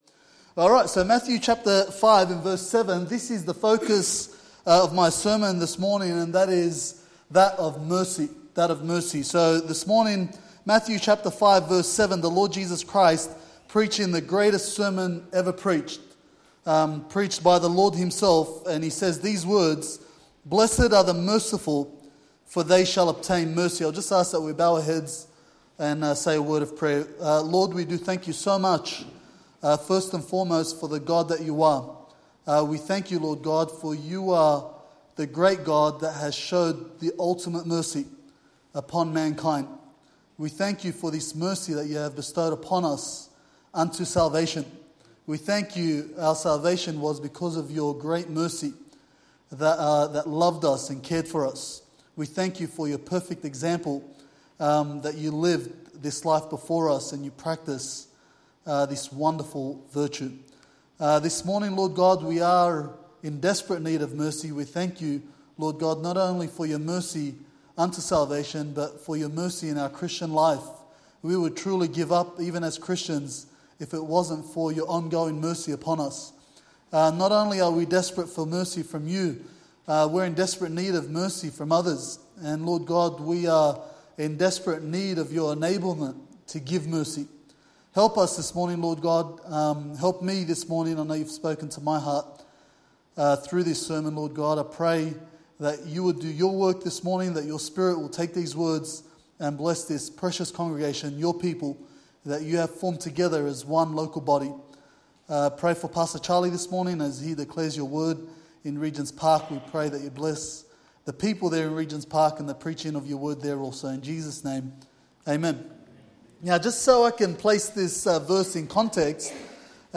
10:30am Preacher